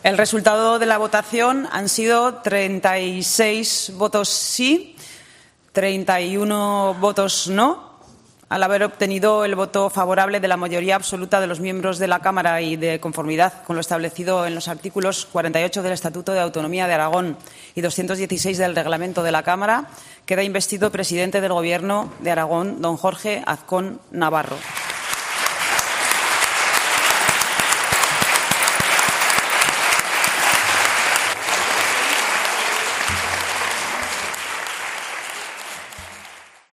La presidenta de las Cortes, Marta Fernández, anuncia el resultado de la votación de investidura.